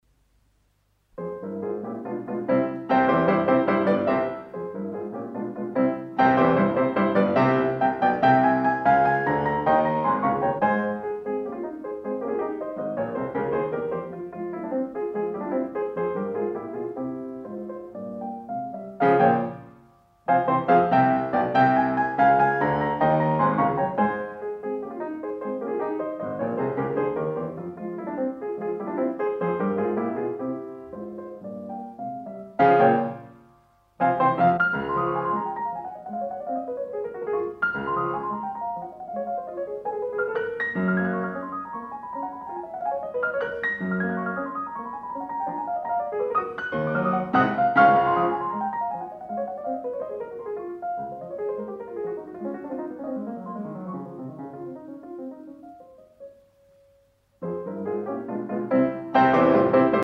A-flat Major